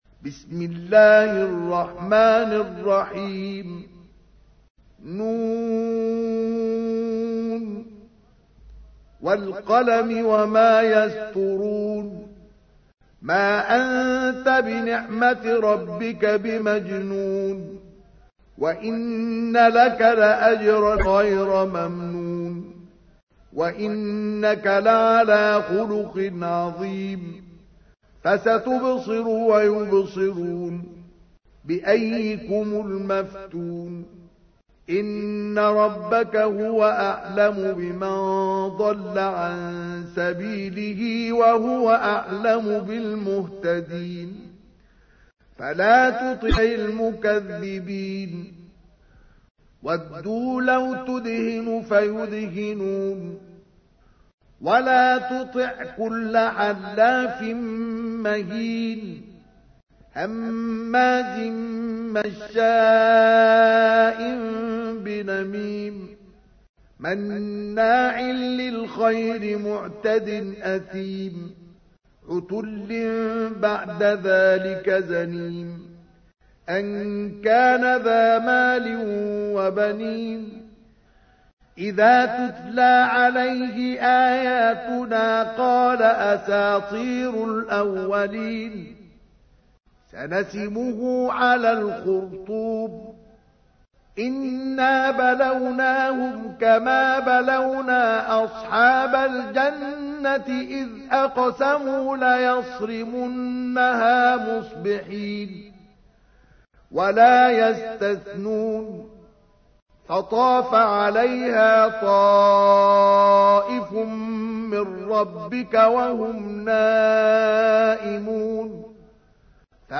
تحميل : 68. سورة القلم / القارئ مصطفى اسماعيل / القرآن الكريم / موقع يا حسين